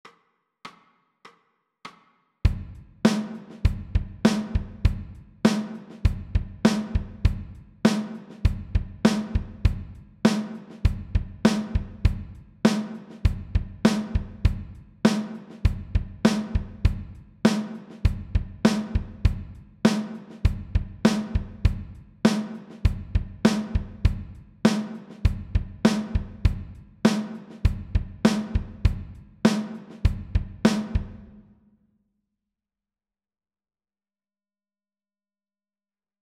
TRANSCRIBING DRUM GROOVES
You will hear a 4 beat intro followed by a one bar drum groove repeated 12 times.